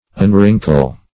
Search Result for " unwrinkle" : The Collaborative International Dictionary of English v.0.48: Unwrinkle \Un*wrin"kle\, v. t. [1st pref. un- + wrinkle.] To reduce from a wrinkled state; to smooth.